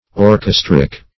Orchestric \Or*ches"tric\, a.